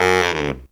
Index of /90_sSampleCDs/Best Service ProSamples vol.25 - Pop & Funk Brass [AKAI] 1CD/Partition C/BARITONE FX2